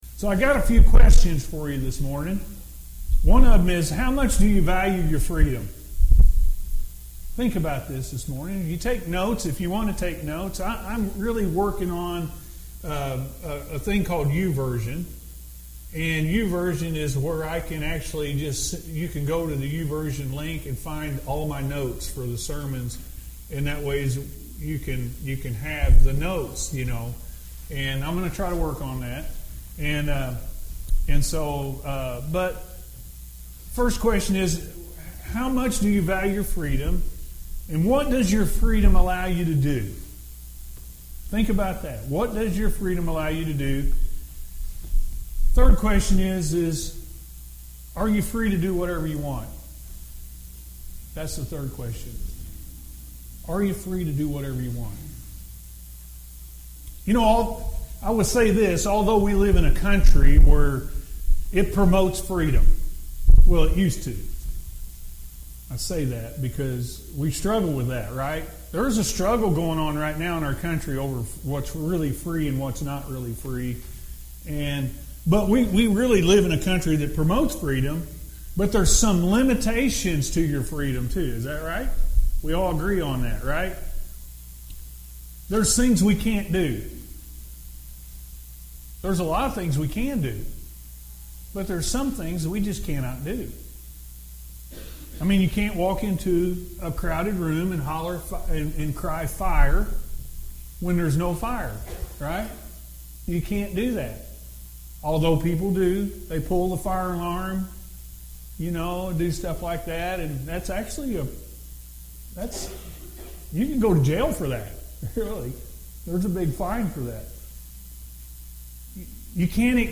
Freedom In Christ-A.M. Service – Anna First Church of the Nazarene